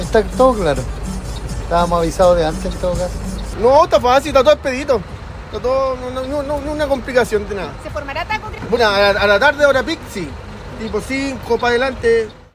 “Estábamos avisados de antes” y “ninguna complicación”, comentaron los choferes, sin embargo, advirtieron por la congestión que se pueda ocasionar pasadas las cinco de la tarde.